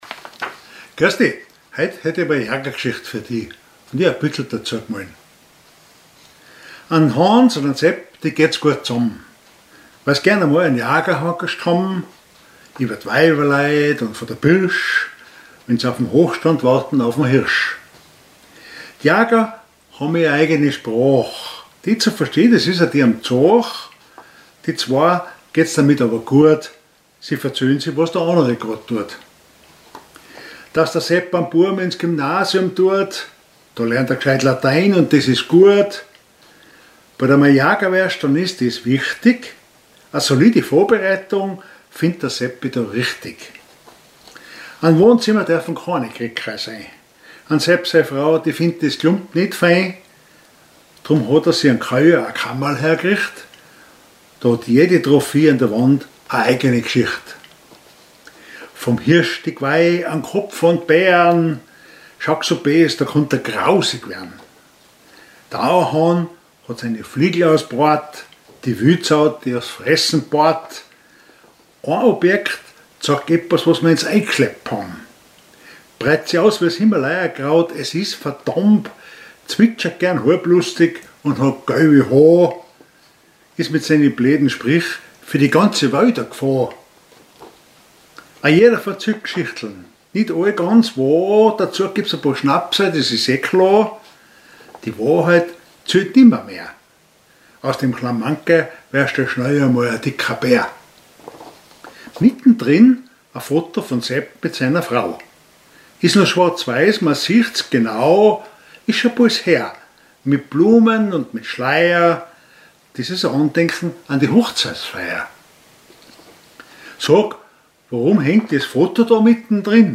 375 Hochzeitsfoto Gedicht August 2018 aus einem Jaga-Hoangascht